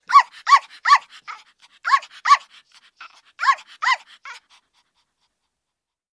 На этой странице собраны разнообразные звуки, связанные с померанскими шпицами: от звонкого лая щенков до довольного поскуливания.
Звук лая щенка померанского шпица